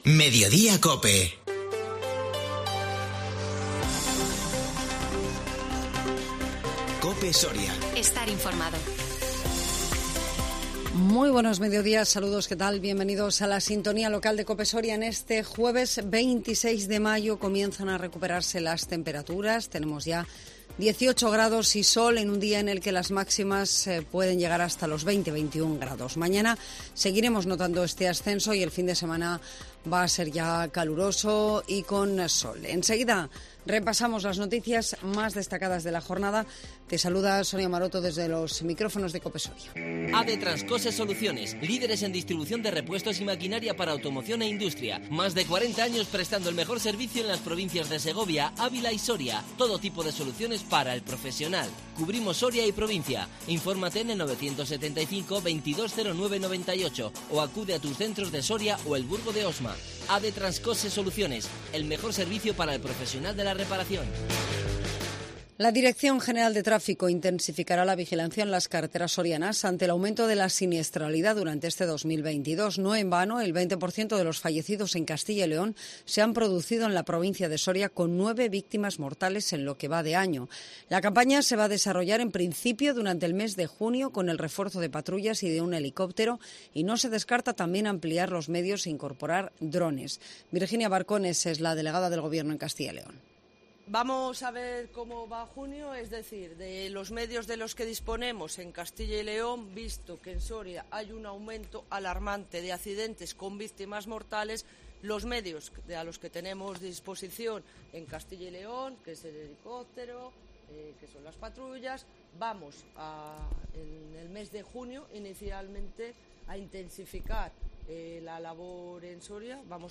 INFORMATIVO MEDIODÍA COPE SORIA 26 MAYO 2022